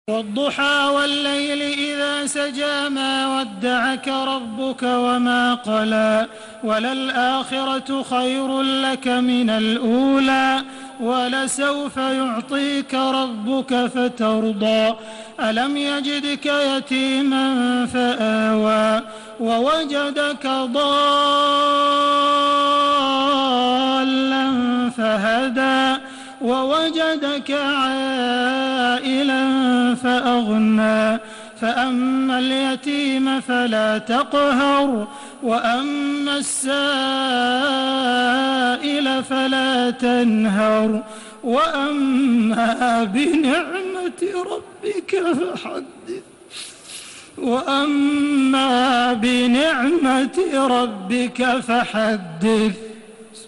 Surah আদ্ব-দ্বুহা MP3 by Makkah Taraweeh 1432 in Hafs An Asim narration.
Murattal